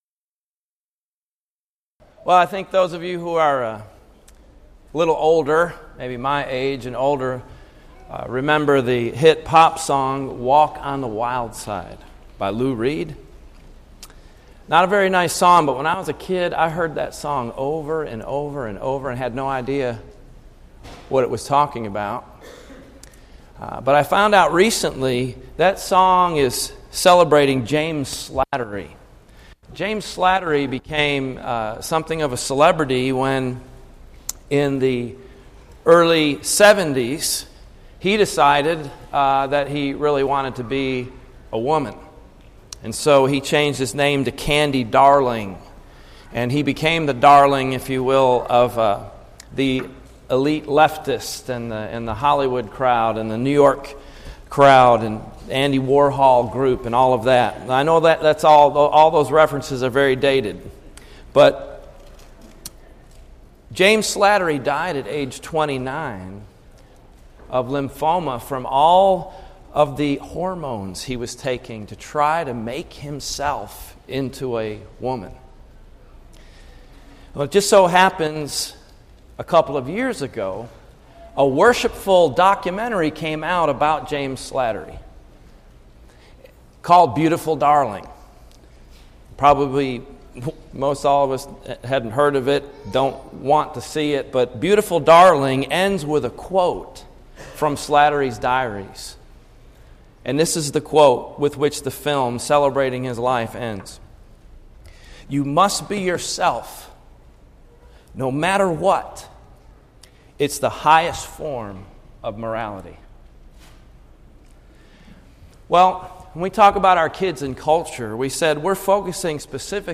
Event: 2016 Focal Point
lecture